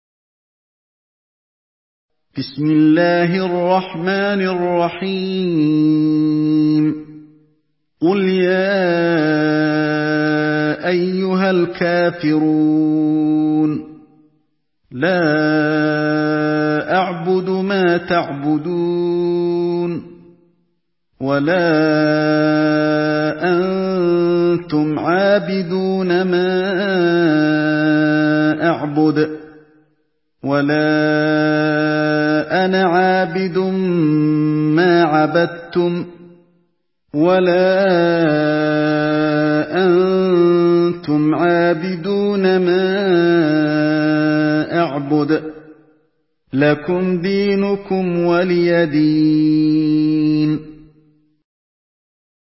تحميل سورة الكافرون بصوت علي الحذيفي
مرتل